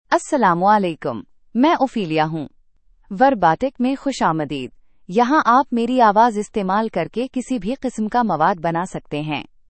Ophelia — Female Urdu (India) AI Voice | TTS, Voice Cloning & Video | Verbatik AI
FemaleUrdu (India)
Ophelia is a female AI voice for Urdu (India).
Voice sample
Listen to Ophelia's female Urdu voice.
Ophelia delivers clear pronunciation with authentic India Urdu intonation, making your content sound professionally produced.